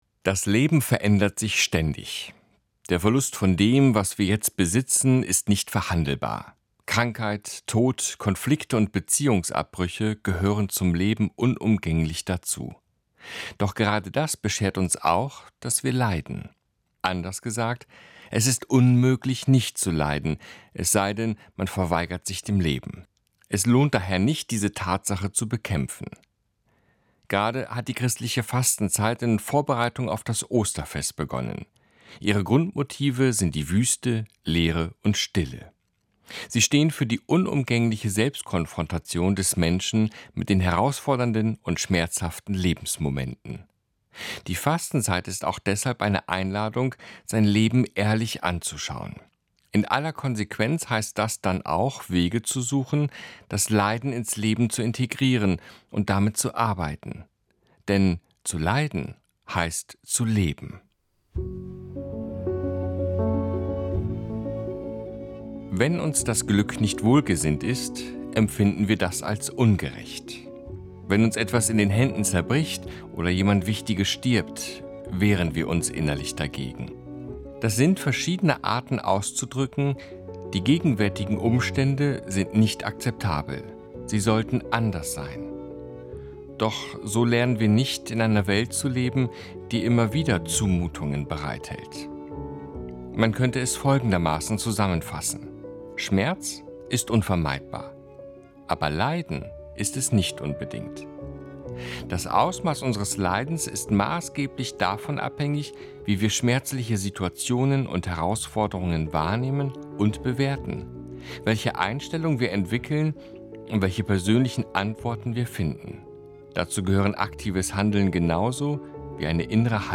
Predigten
Predigt_DLF_Wie-man-gut-leidet.mp3